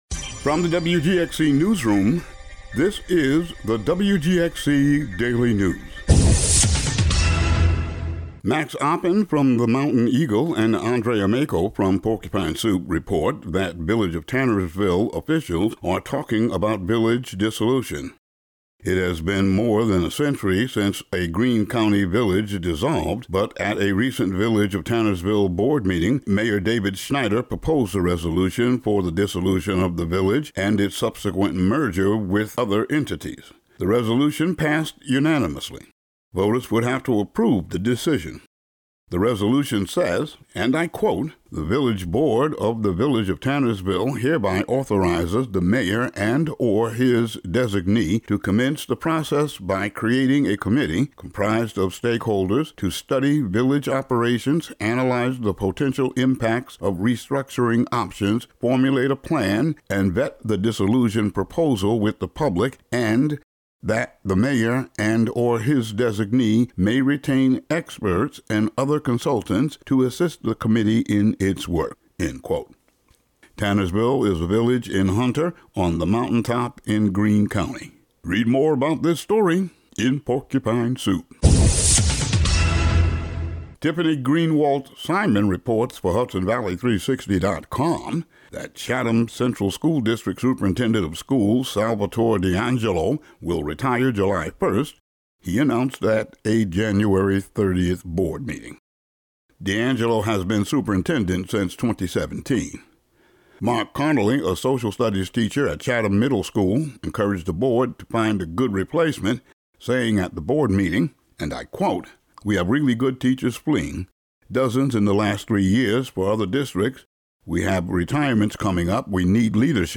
Today's audio daily news update.
Today's daily local audio news.